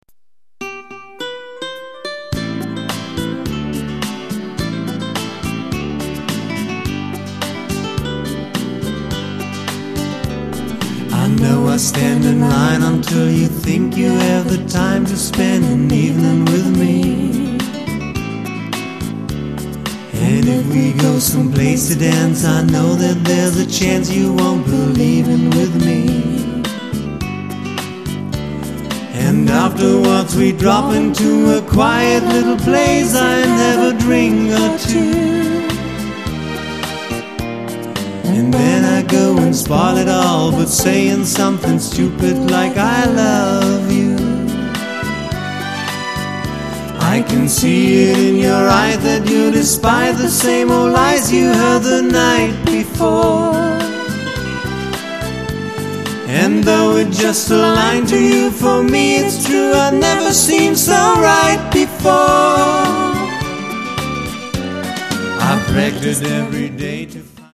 Tanz und Unterhaltungsmusik
• Coverband